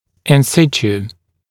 [ˌɪn’sɪtjuː][ˌин’ситйу:]на своём месте